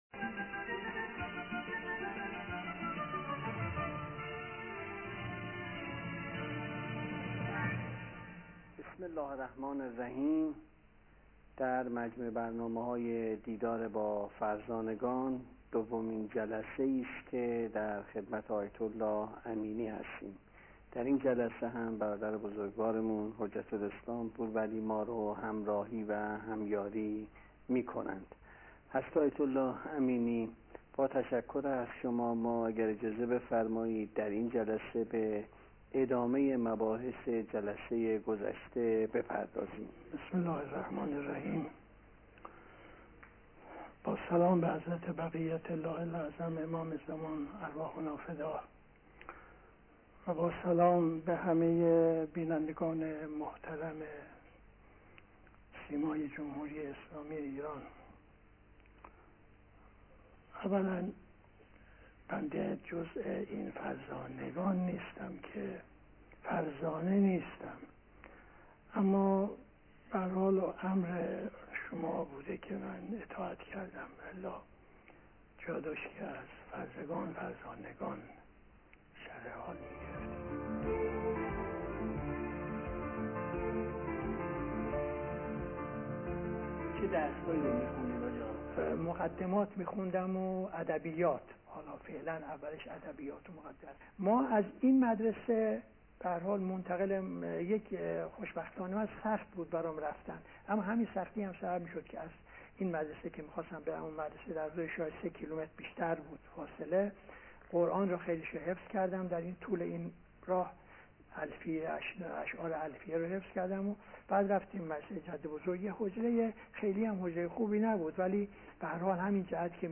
صوت / زندگی‌نامه آیت‌الله امینی از زبان خودشان (جلسه دوم)